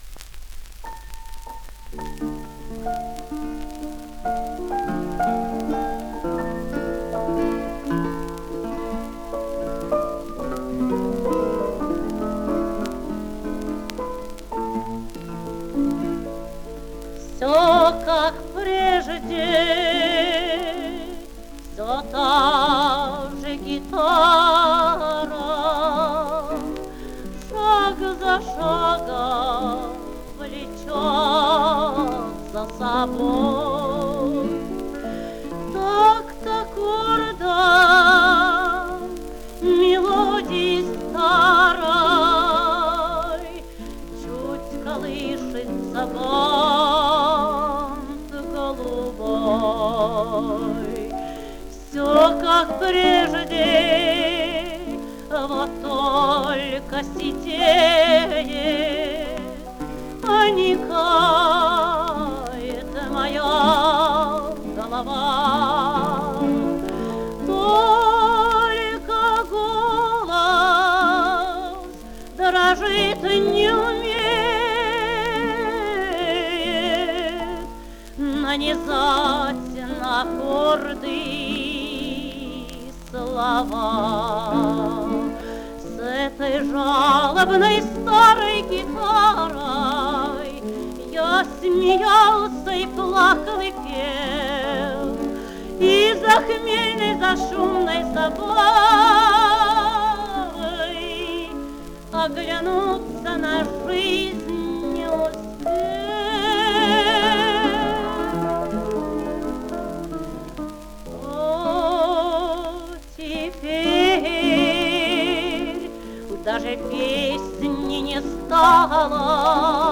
Каталожная категория: Контральто с фортепиано и гитарами |
Жанр: Романс
Вид аккомпанемента: Фортепиано и 3 гитары |